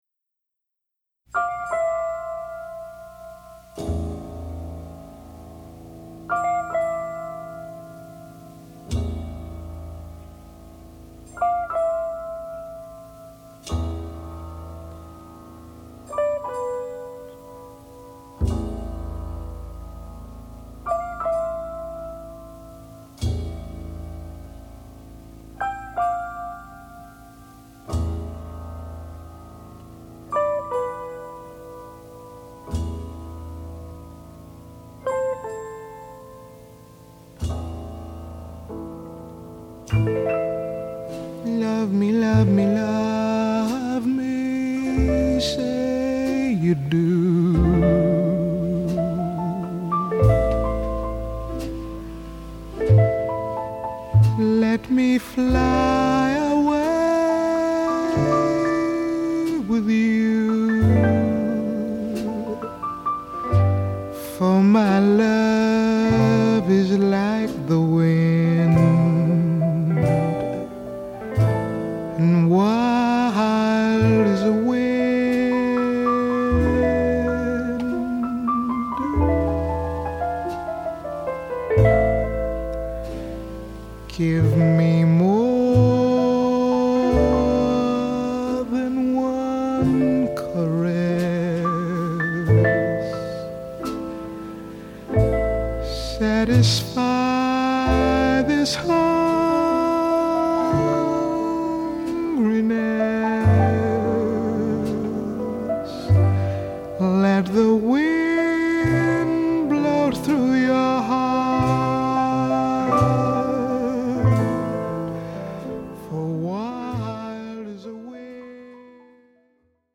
★ 八位爵士天后與六位爵士天王傳世名曲！